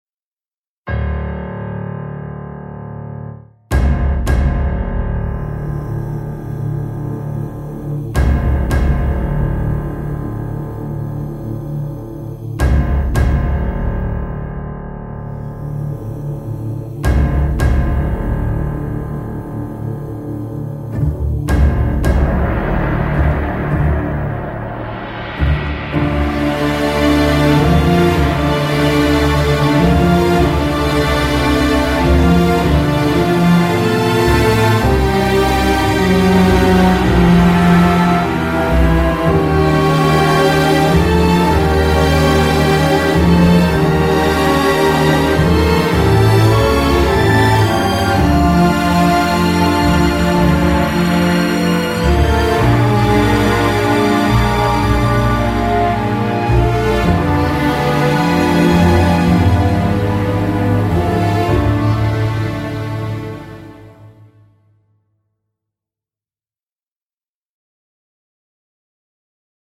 Free Download Instrumental